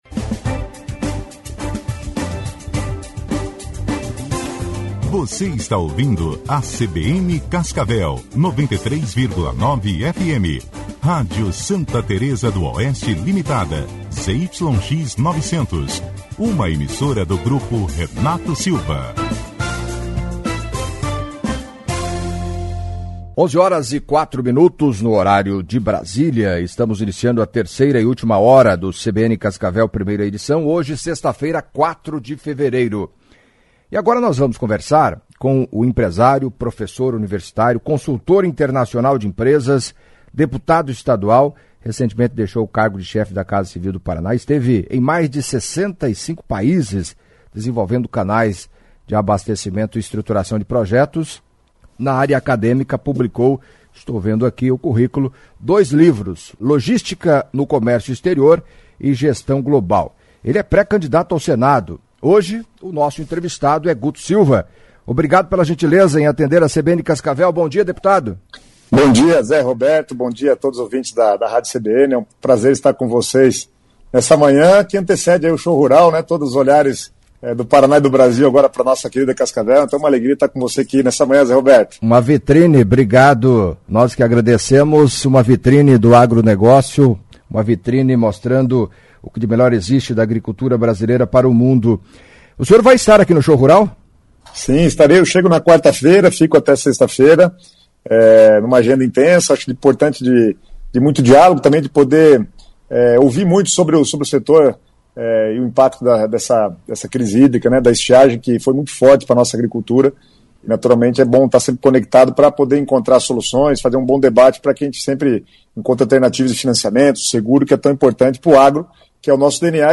Em entrevista à CBN Cascavel nesta sexta-feira (04) o deputado estadual Guto Silva do PSD, que recentemente deixou o cargo de chefe da Casa Civil do Paraná e é pré-cadidato ao Senado falou, entre outros assuntos, da possibilidade de deixar o partido, como anda o relacionamento com o governador Ratinho Júnior, montagem do palanque nacional no Paraná, economia, pandemia. Sobre o pedágio, Guto Silva destacou o prejuízo deixado pelas concessionárias e espera ainda que elas paguem os valores estimados em R$ 10 bilhões por obras não realizadas no Paraná, durante o prazo de vigência dos contratos.